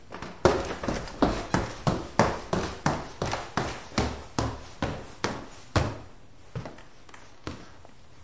TEC 步行的声音 " Foley 走下楼梯的声音
描述：走在人字拖鞋的木台阶。
标签： 英尺 硬木 拟音 楼梯 台阶 走路的脚步声 地板
声道立体声